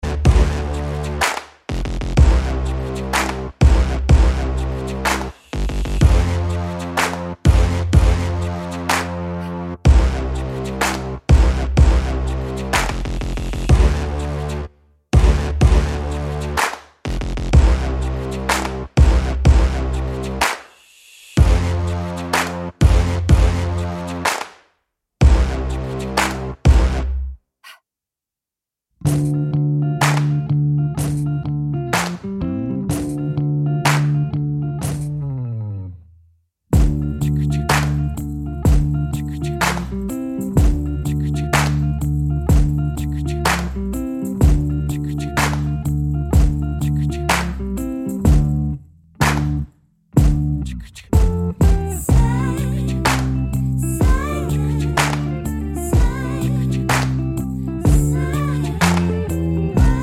no Backing Vocals Rock 3:35 Buy £1.50